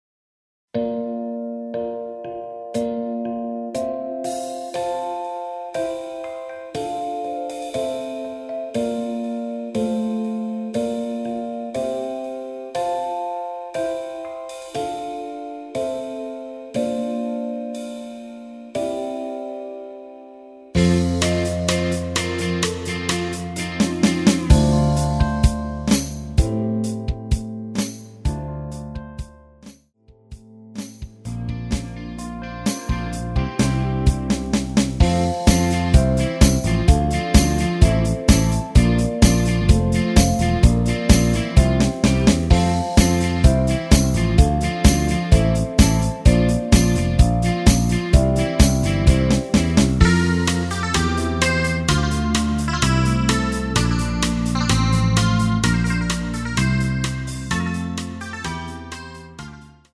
(Hi-Hat 소리 끝난후노래 시작) 키 F 가수